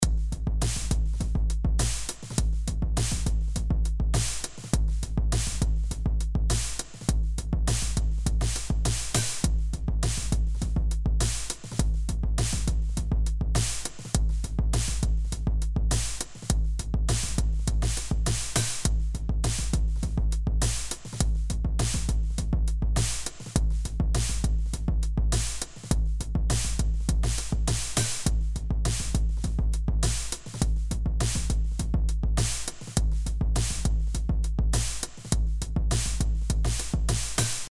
Создал 2 моно развёл L и R с моно 160 туда направил барабаны.
При наличии ревера на барабанах изменением трешхолда компрессора и компенсированием громкости ручкой "майкап" получается довольно прикольный FX "летающего" снейра что свою очередь может создать офигенный грув на других инструментах...
Ярко выраженная паннорммма R.mp3